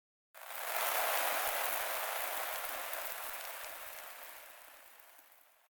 На этой странице собраны звуки песчаной бури – от далёкого гула до оглушительного вихря.
Песок движется под напором ветра